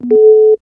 2. Single Beep (
beep.wav